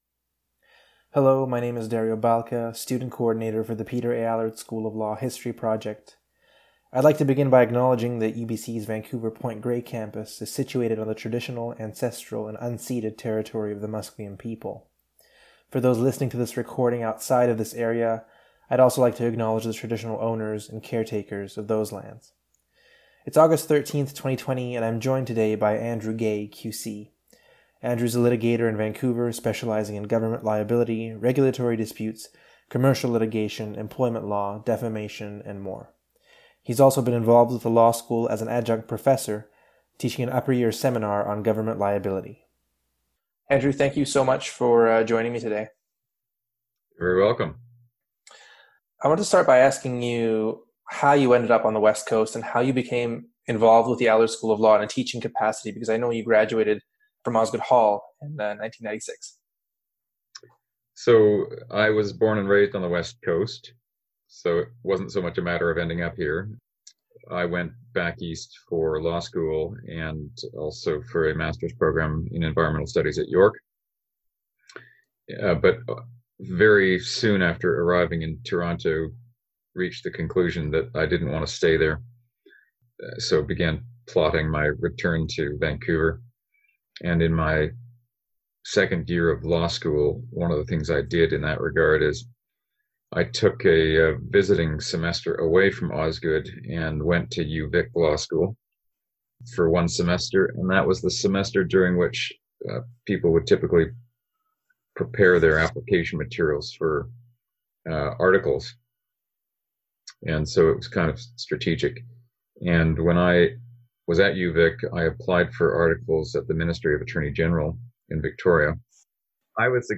Listen to the History Project's 2020 interview